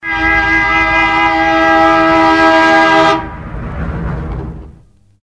Категория: Живые звуки, имитация